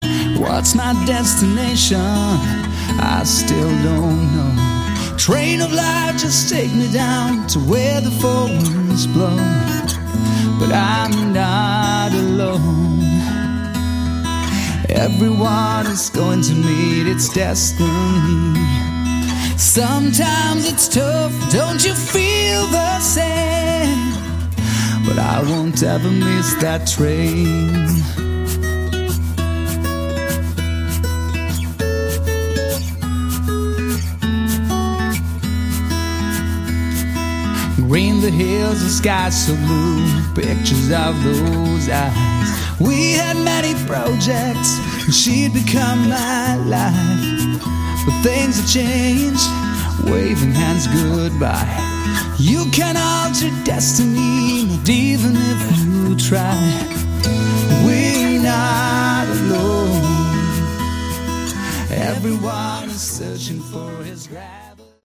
Category: Melodic Hard Rock
unreleased studio track